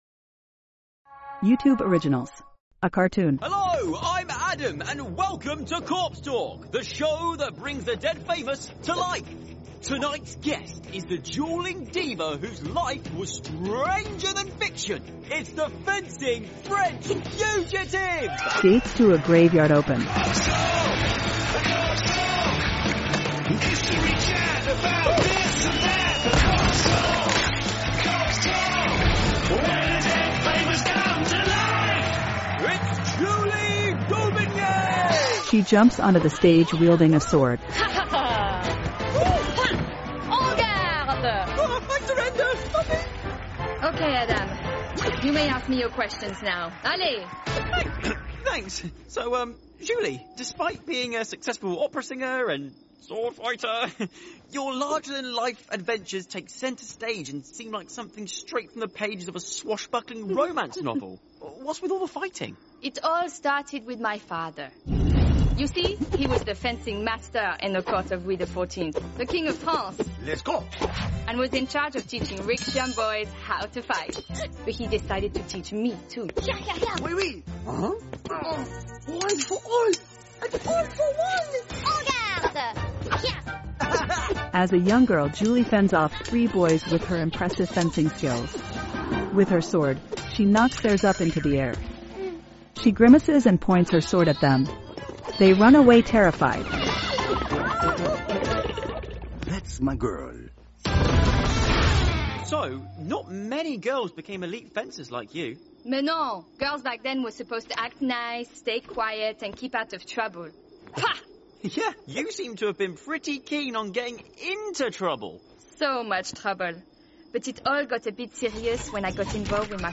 Clear, Fresh, Bright, Crisp, Friendly, Engaging